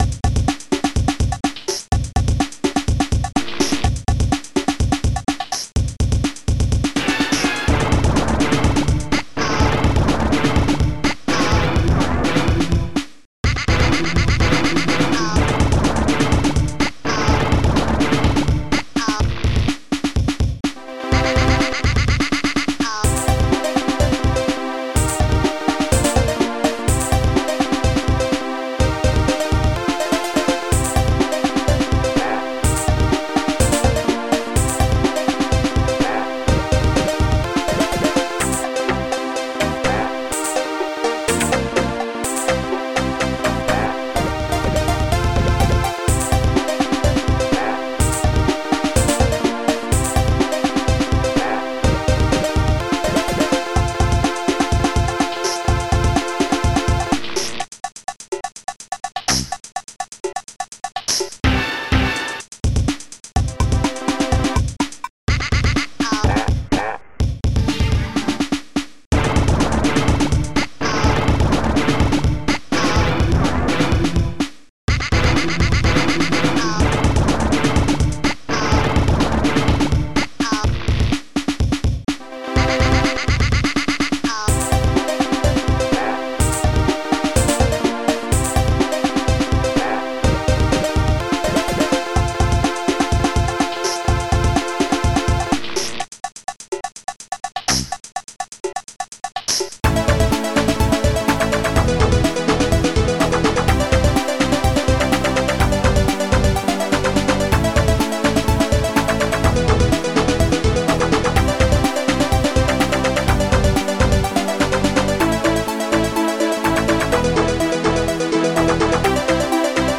Protracker and family
st-01:bassdrum3
st-42:floyd-string2
st-98:blippbass
st-98:hornblast
st-98:cowbell.cfn